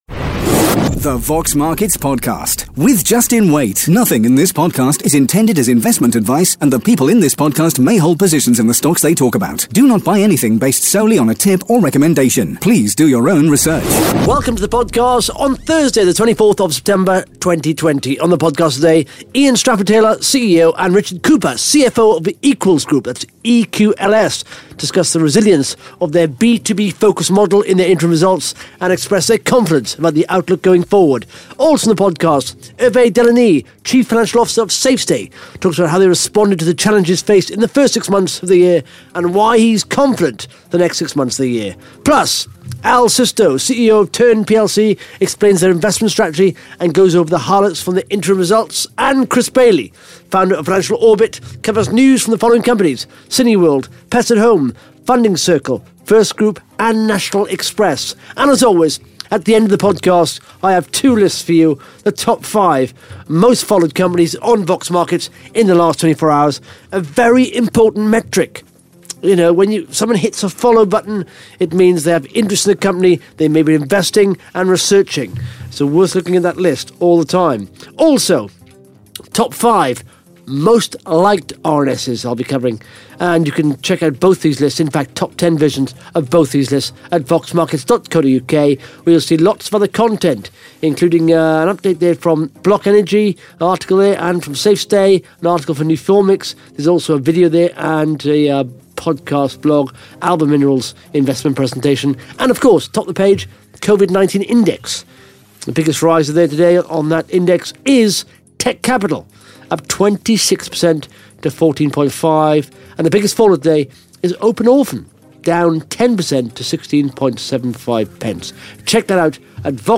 (Interview starts at 18 minutes 18 seconds)